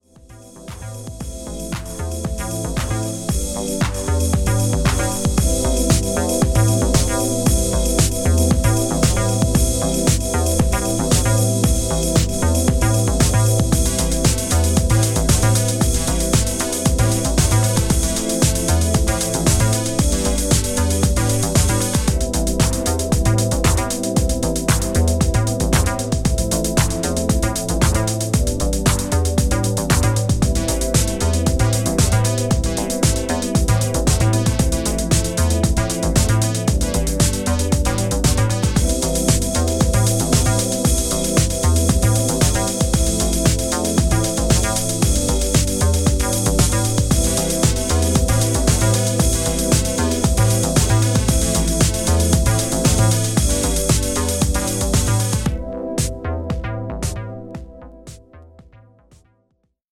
‘80S/’90’sハウス名曲集。